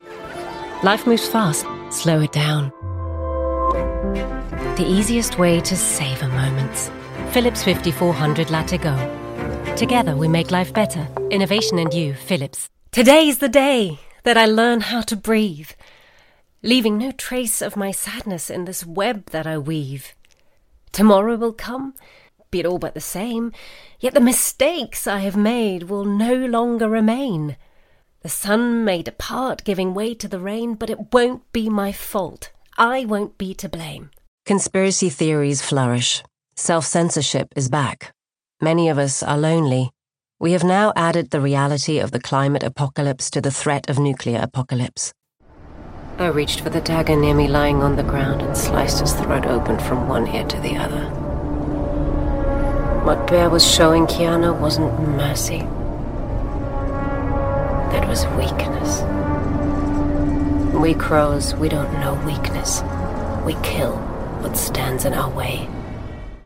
European, German, Female, Home Studio, 30s-50s